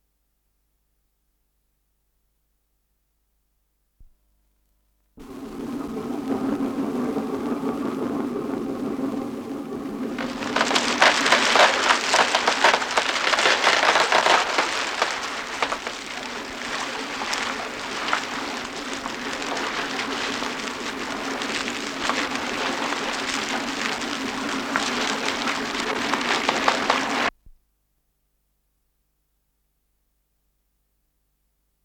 Н-789 — Шум ломающегося льда — Ретро-архив Аудио
с профессиональной магнитной ленты
РедакцияШумовая
Скорость ленты38 см/с
ВариантМоно